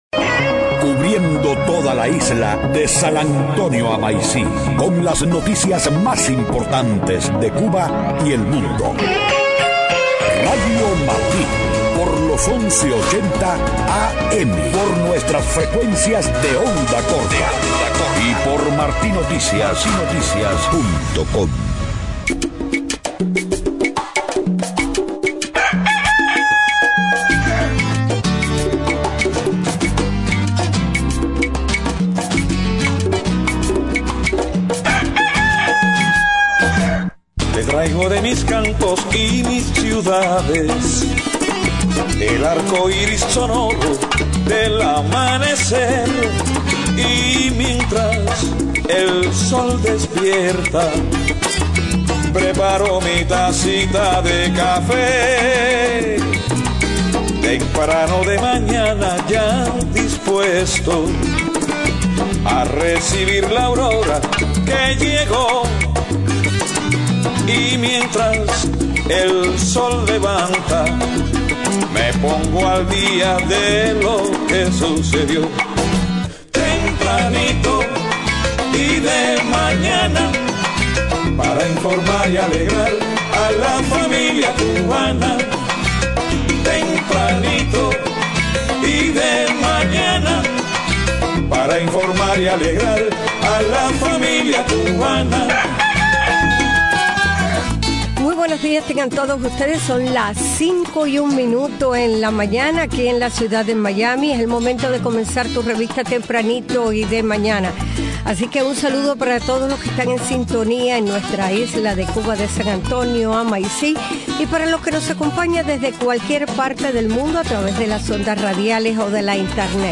Estado del tiempo. Deportes.